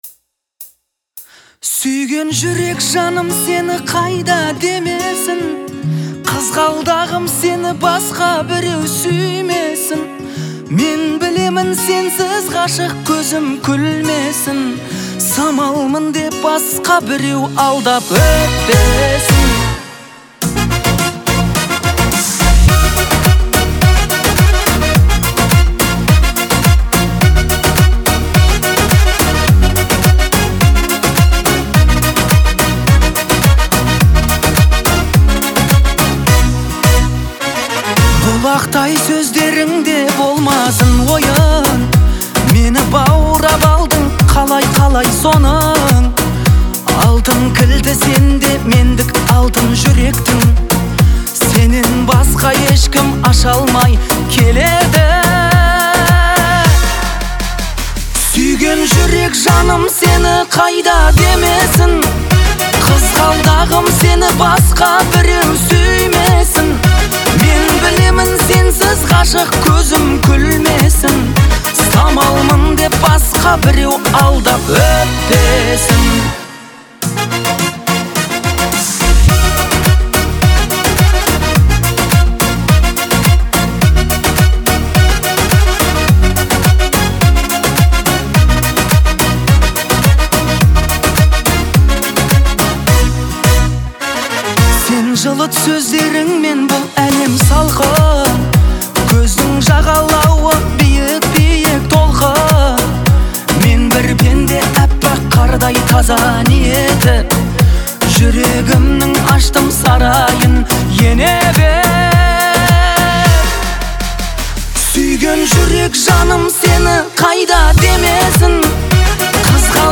это романтичный трек в жанре казахской поп-музыки